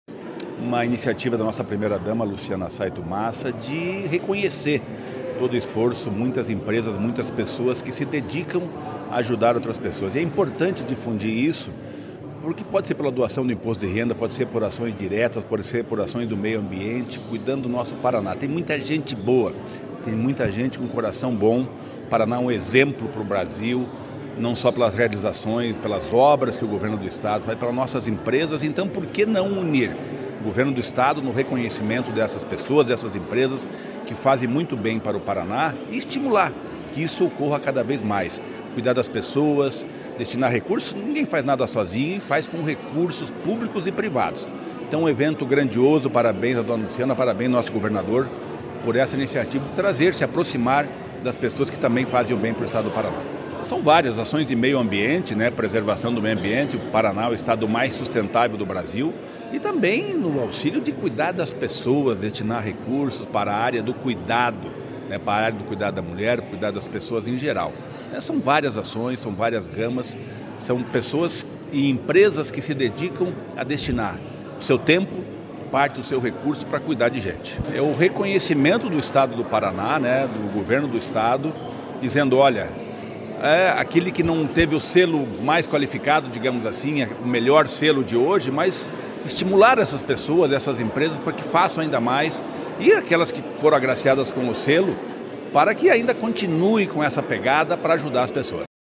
Sonora do secretário do Desenvolvimento Social e Família, Rogério Carboni, sobre o Selo Solidário para empresas que promovem desenvolvimento social e sustentável